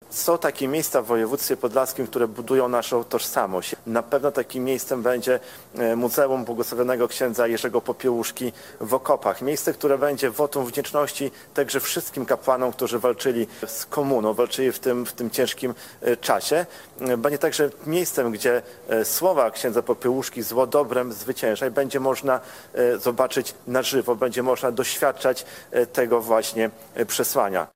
Ok. 20 mln zł zapewni Województwo Podlaskie – mówił marszałek Łukasz Prokorym: